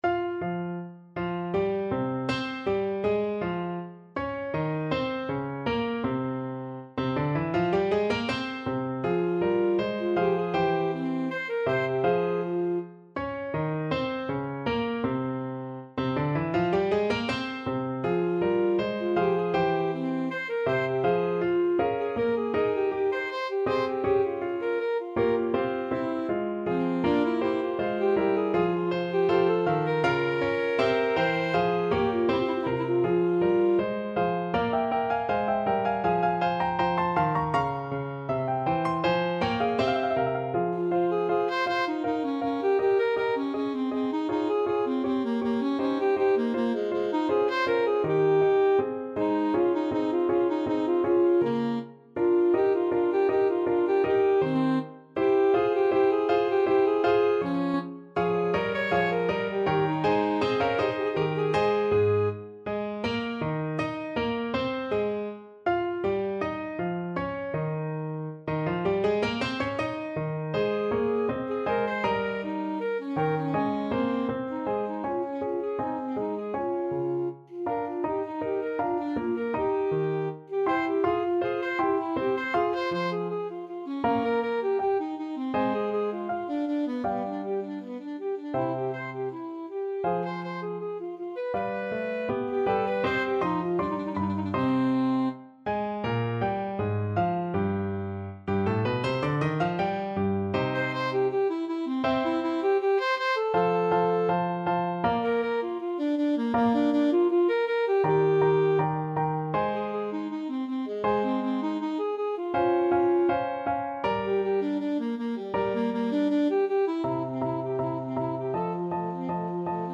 Alto Saxophone
4/4 (View more 4/4 Music)
F minor (Sounding Pitch) D minor (Alto Saxophone in Eb) (View more F minor Music for Saxophone )
Andante e spiccato
Classical (View more Classical Saxophone Music)